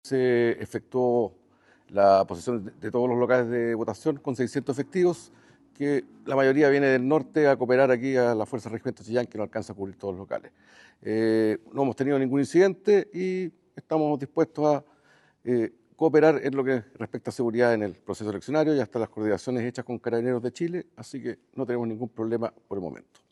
El anuncio se realizó en un punto de prensa efectuado la jornada del sábado, donde participaron el Jefe de la Defensa Nacional en Ñuble, general Patricio Valdivia, el director regional del Servel, Aldo Valenzuela, y la general jefe de zona de Carabineros, Loreto Osses.